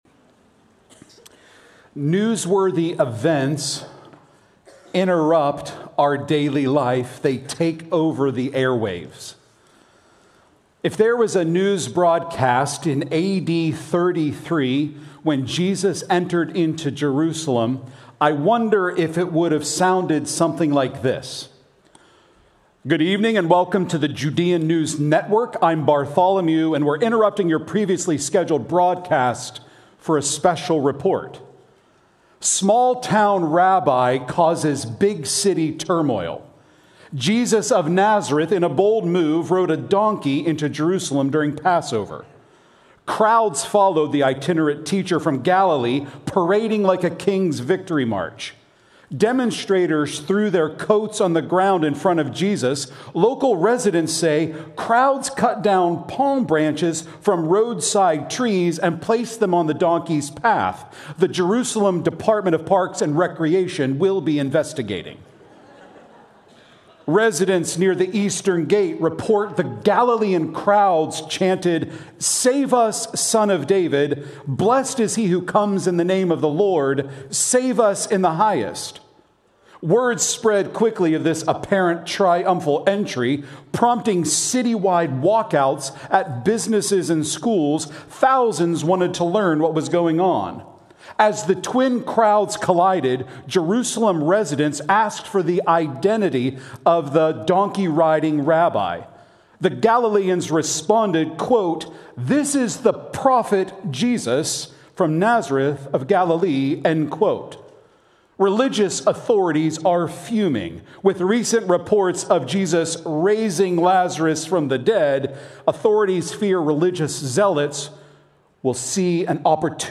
Prev Previous Sermon Next Sermon Next Title Behold Your King .